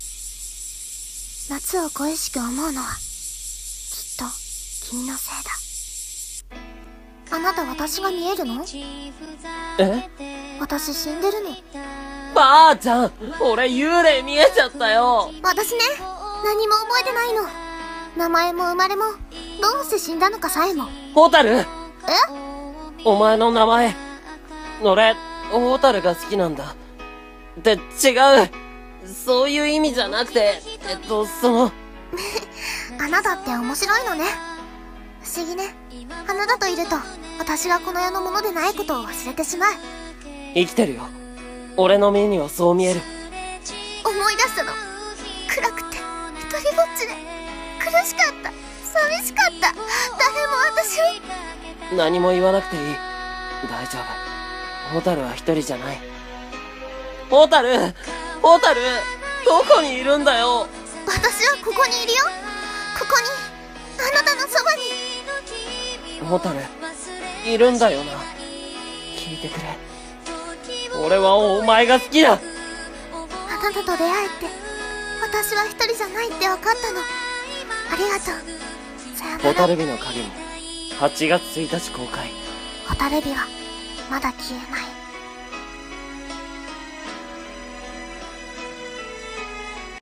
【声劇】映画告知風CM